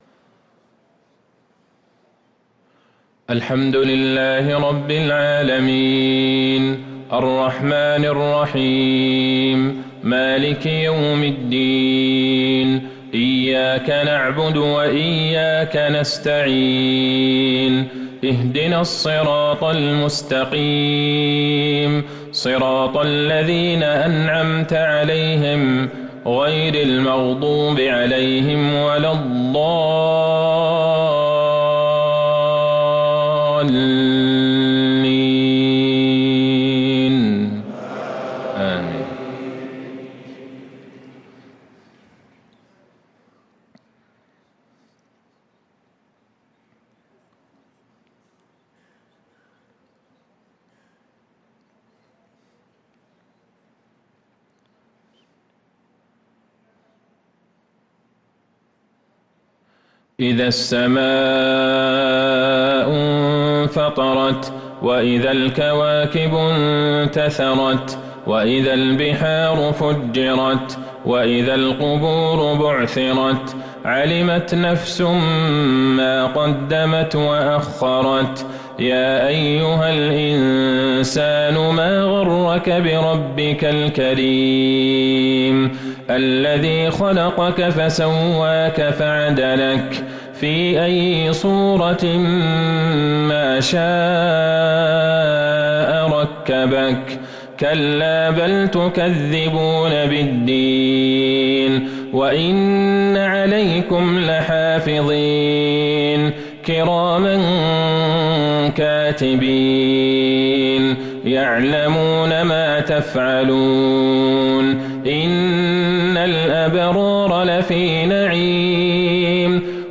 صلاة العشاء للقارئ عبدالله البعيجان 27 محرم 1445 هـ